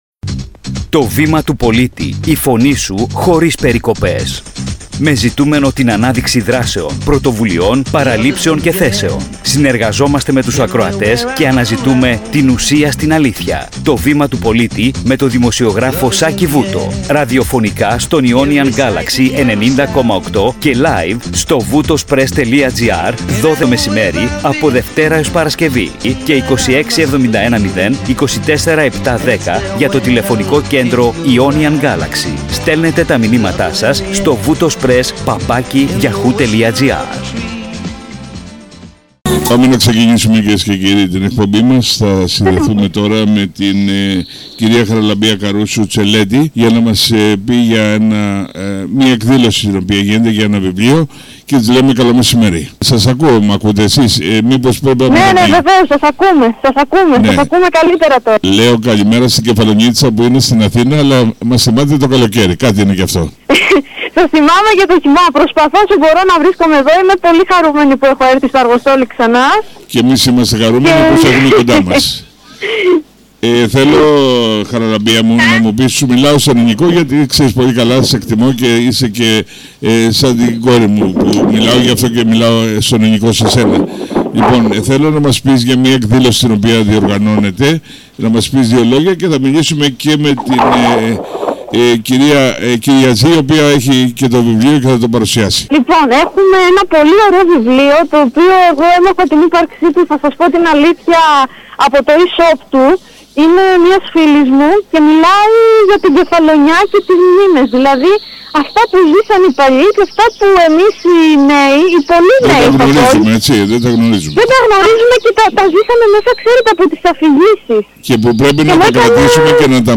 Πυρήνας της Συζήτησης: